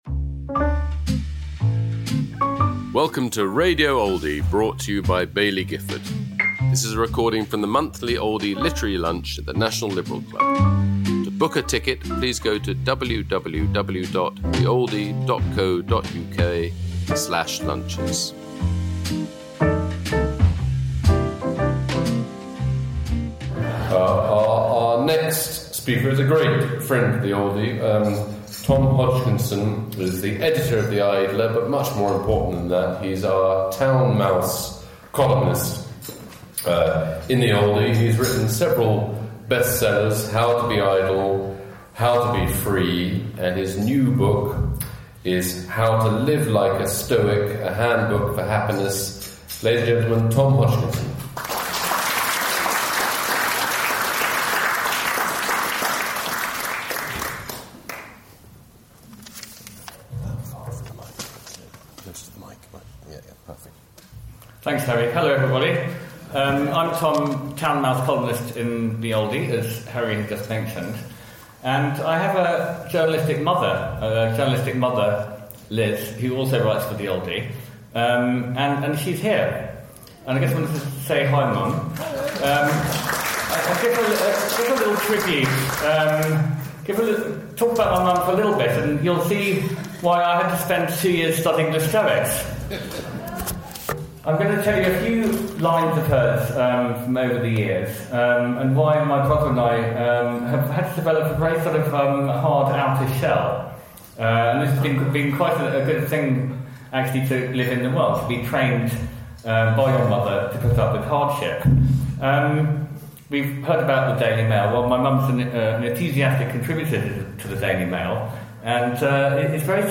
Tom Hodgkinson speaking about his new book, How to Live Like a Stoic: a Handbook for Happiness, at the Oldie Literary Lunch, held at London’s National Liberal Club, on February 17th 2026.